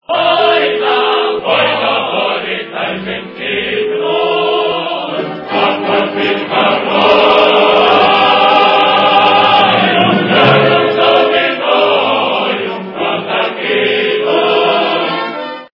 » Реалтоны » народные » Украинская народная песня
качество понижено и присутствуют гудки.